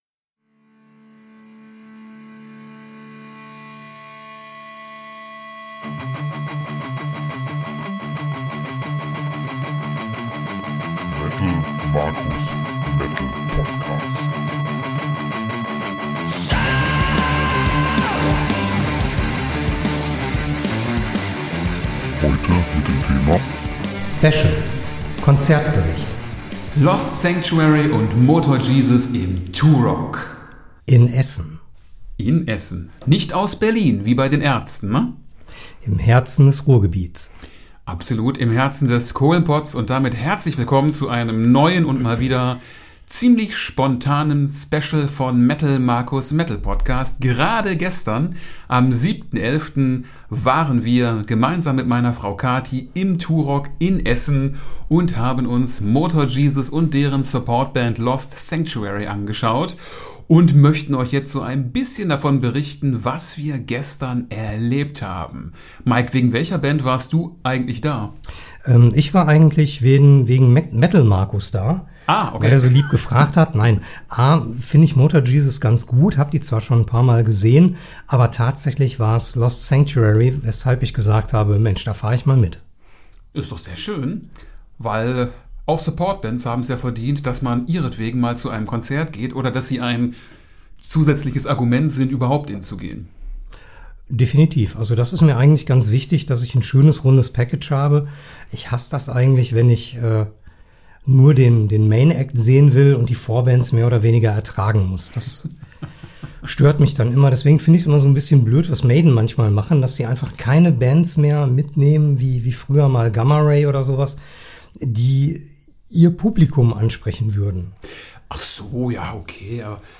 Konzertbericht